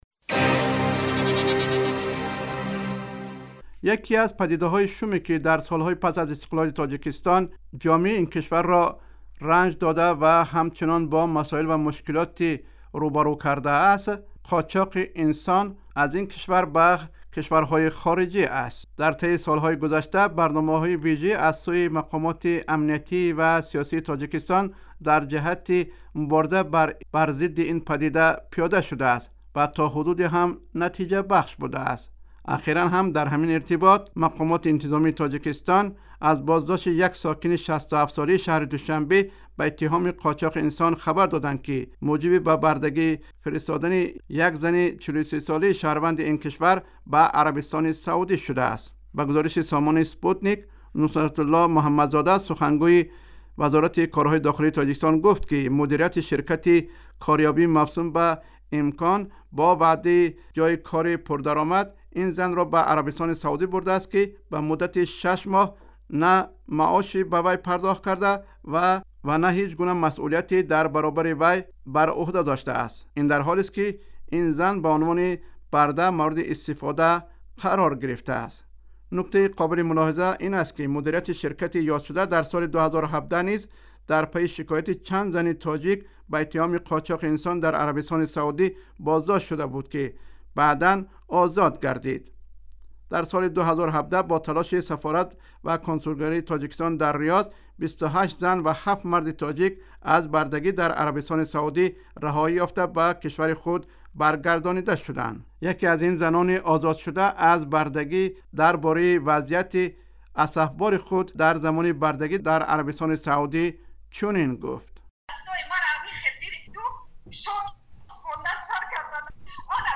Гузориши вижа: қочоқи инсон дар Тоҷикистон